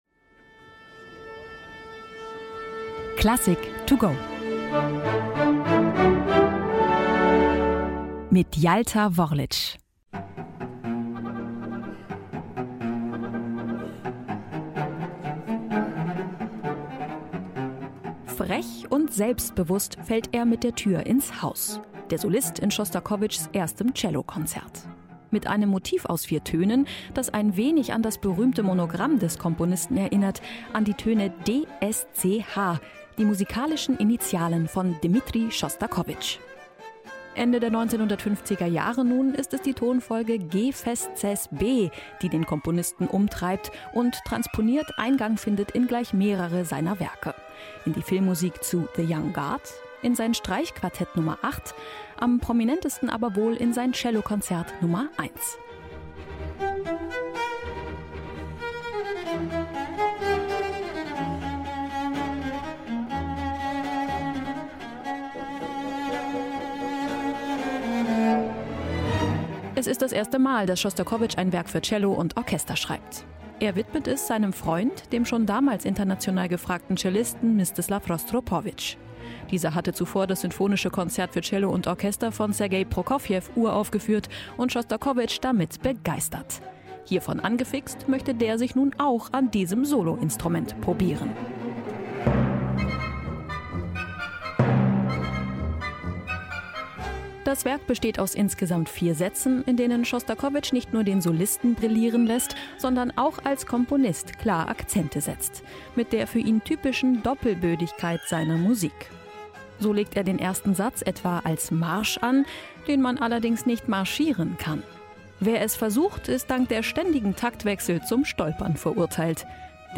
Eine kurze Werkeinführung für unterwegs